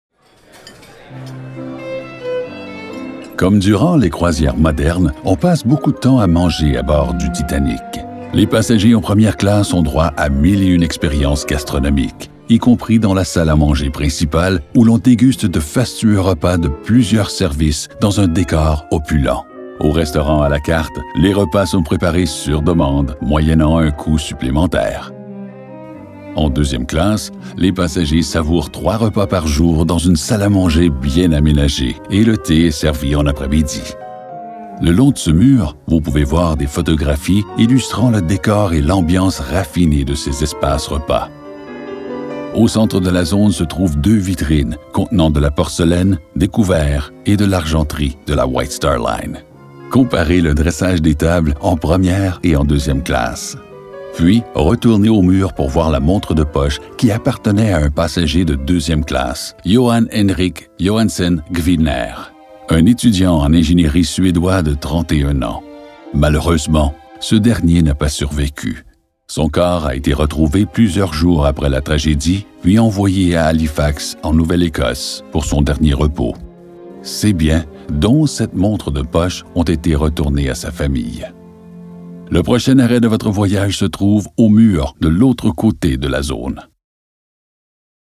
• Le déploiement muséologique extrêmement fort et saisissant génère une vive émotion, décuplée grâce à un audioguide diffusant une trame narrative des plus bouleversantes, redonnant vie à ceux et celles qui ont vécu la funeste nuit du 14 au 15 avril 1912.
titanic-audioguide-gastronomie.mp3